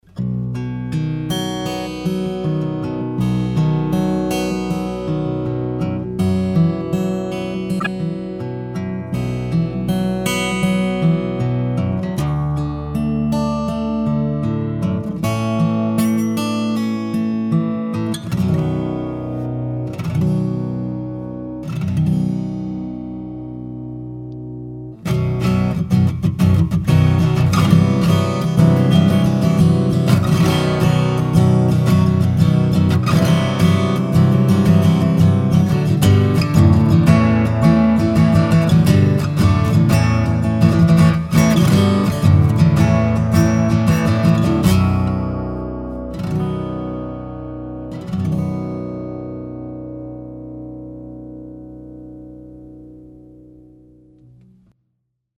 Baritonov� kytara FBM Macho baritone
Macho_FBM_baritone_(H).mp3